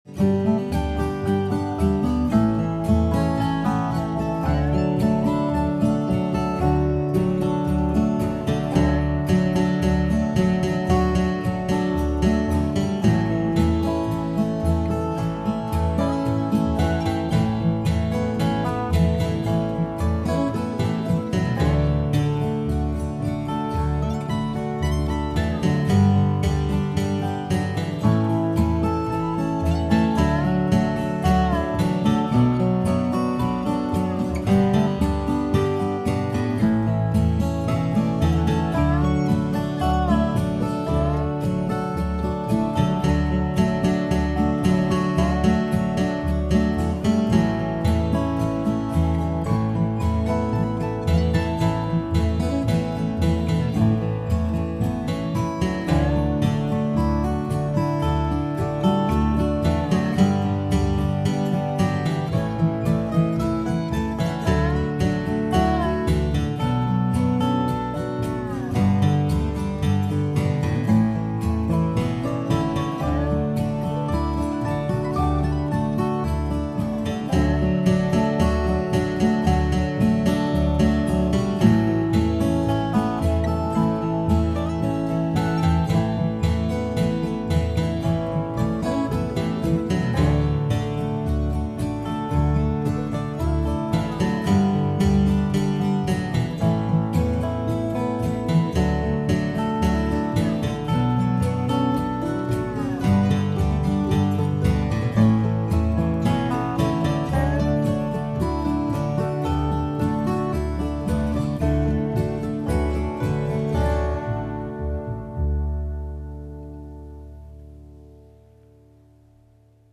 This is much shorter and simpler than the previous ones.
This folk chant sounds like a ripoff of every folk sing ever written but I can’t place it, so the tune, such as it is, is mine until someone tells me what it used to be.